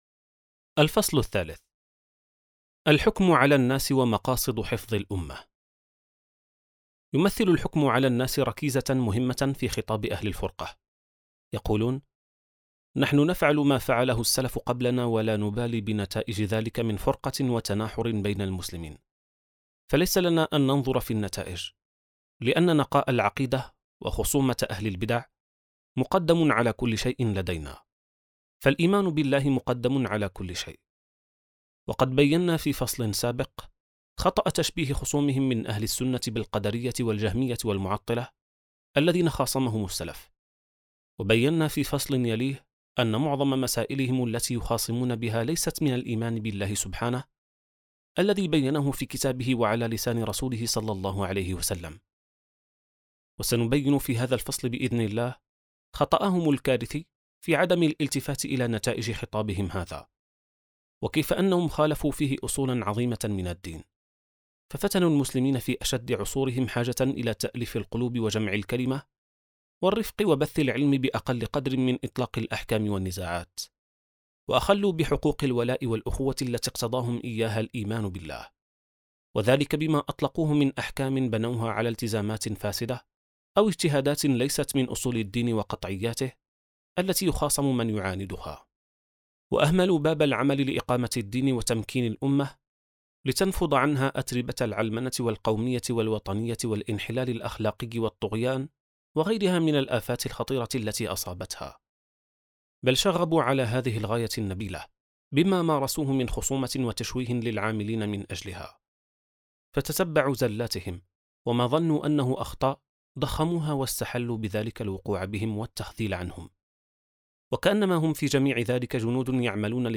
كتاب صوتي | العقائدية القاصرة (48): الفصل الثالث • السبيل